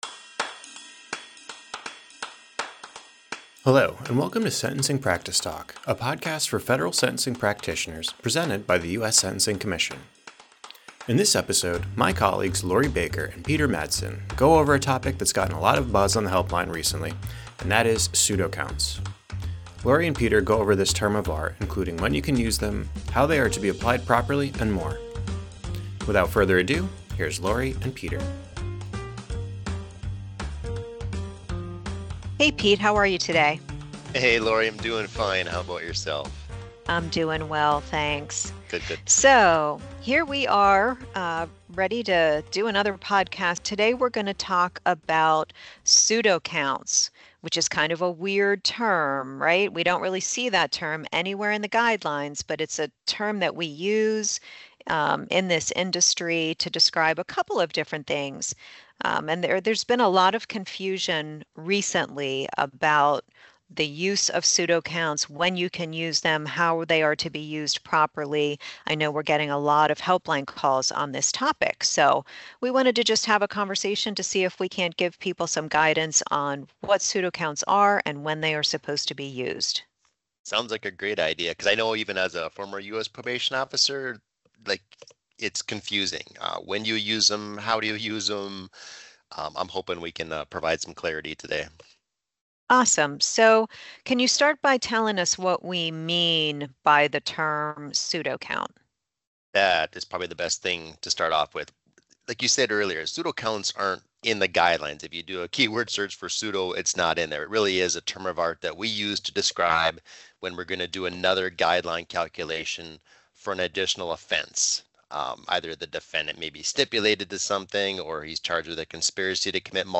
Their conversation provides guidance on the meaning of the term and describes the limited times when pseudocounts can be used.